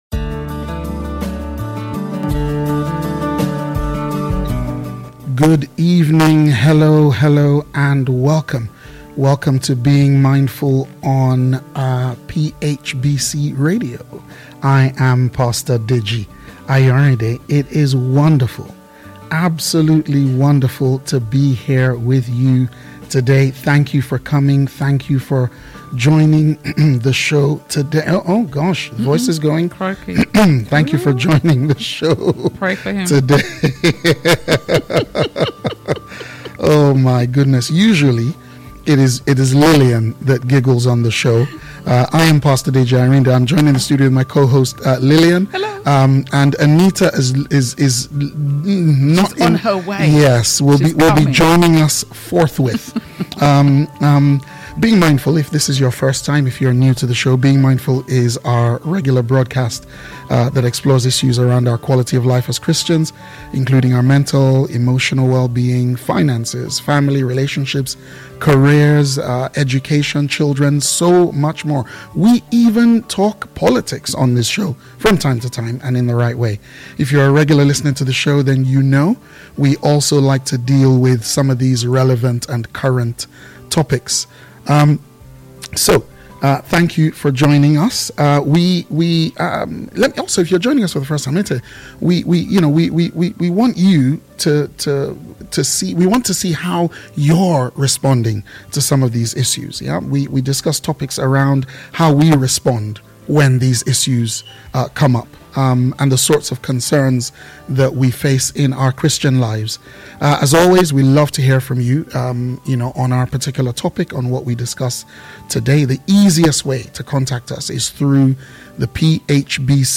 Click to listen or download a recording of this live show.